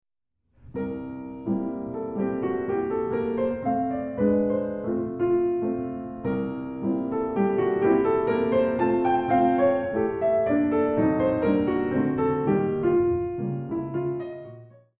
mp3Nepomuceno, Alberto, Quatro peças líricas, No.1 Anhelo, Gracioso e com capricho,